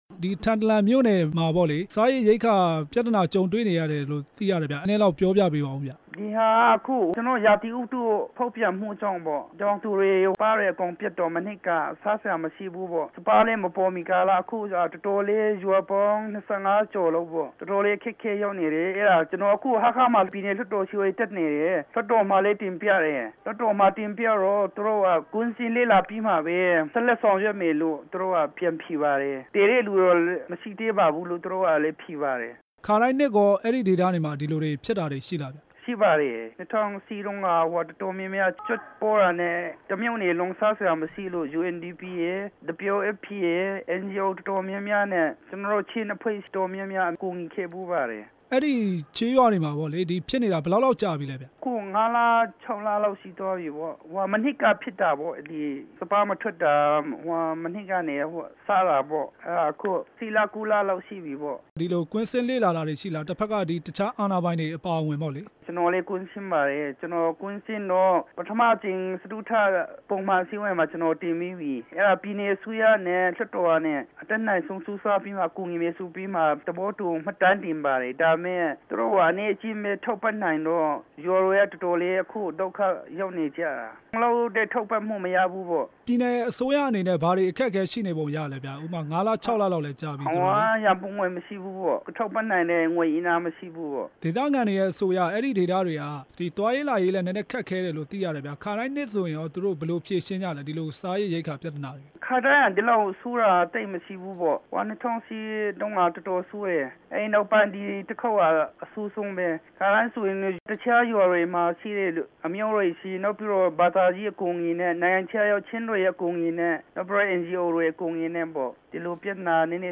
လွှတ်တော်ကိုယ်စားလှယ် လျံမန်းကျုံးနဲ့ မေးမြန်းချက်